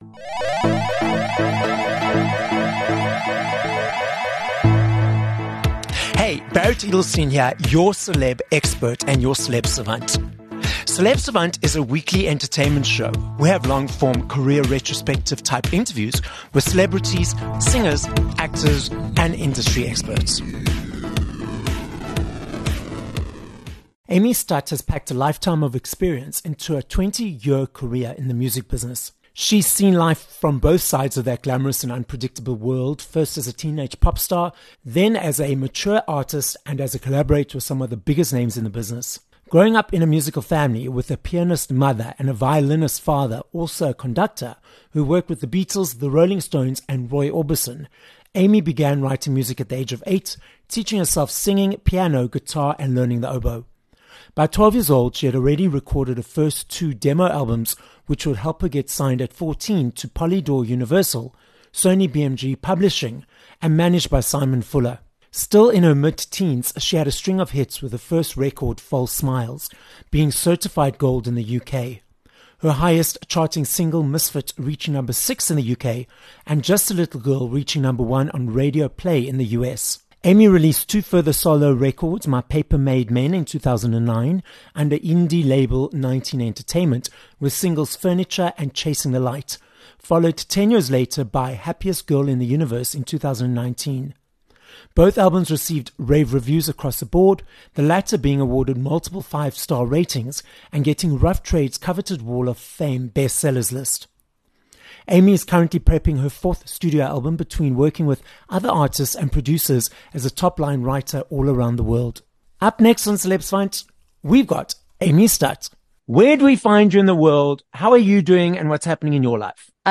4 Sep Interview with Amy Studt
British singer and songwriter Amy Studt is in the guest chair on this episode of Celeb Savant. Amy tells us about growing up in a musical family, starting writing songs at the age of 8, and recording her first album at the age of 12... which was passed on to the right person at the right time.